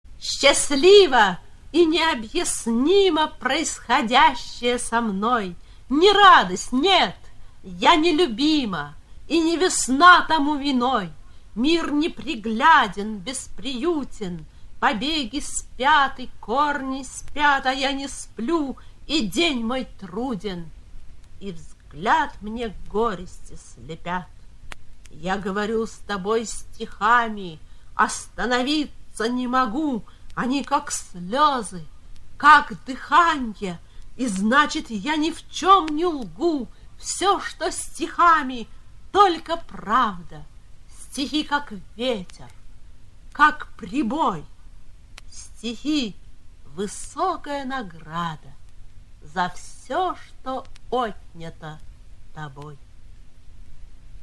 veronika-tushnova-schastlivo-i-neobyasnimo-chitaet-avtor